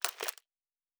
Plastic Foley 05.wav